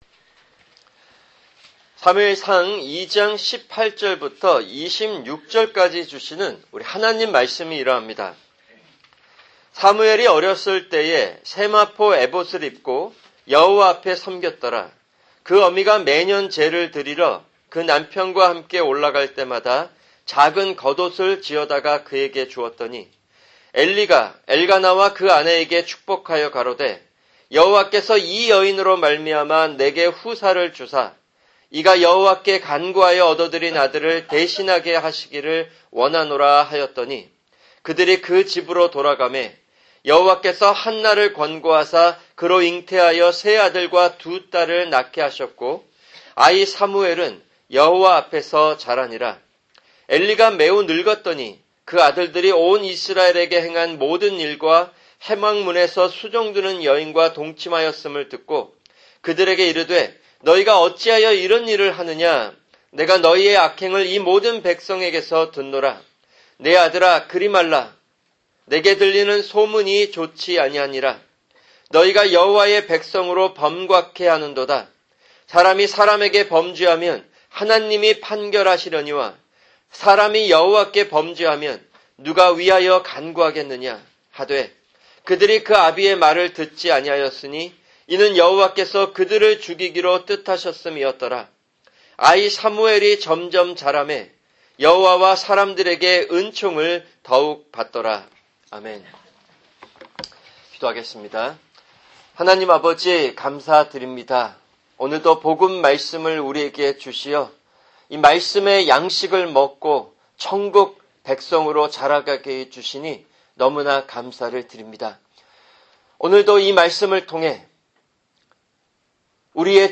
[주일 설교] 사무엘상(7) 2:18-26